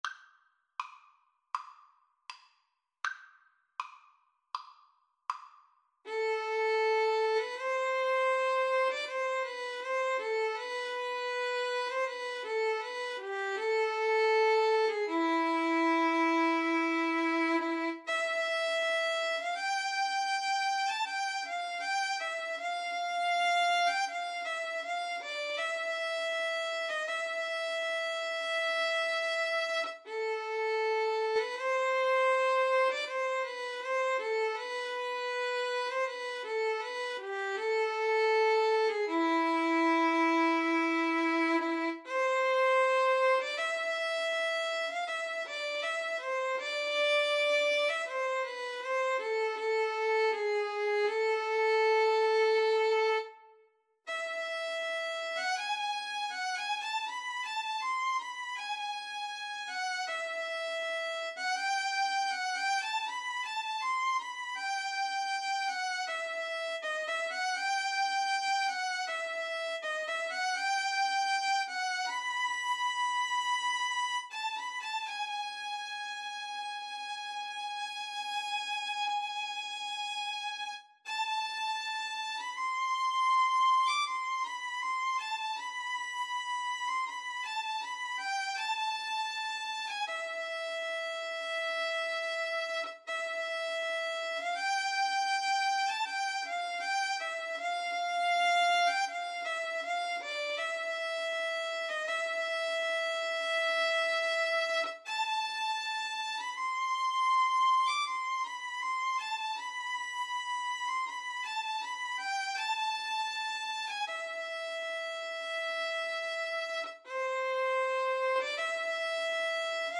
4/4 (View more 4/4 Music)
Andante
Classical (View more Classical Violin-Guitar Duet Music)